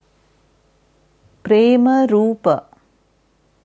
Sanskrit Premarupa korrekte Aussprache anhören
Dieses Wort auf Devanagari schreibt man प्रेमरूप, in der wissenschaftlichen IAST Transliteration premarūpa. Hier kannst du hören, wie man ganz korrekt das Wort Premarupa spricht.